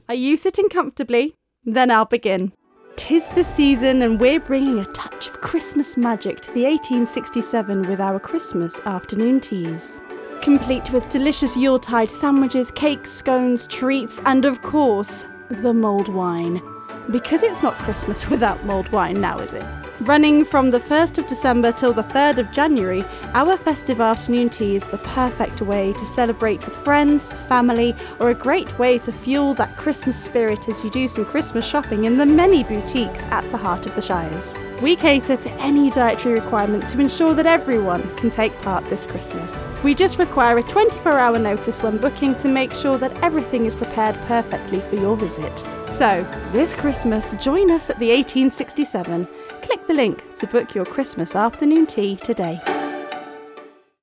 Christmas-Afternoon-tea-VO.mp3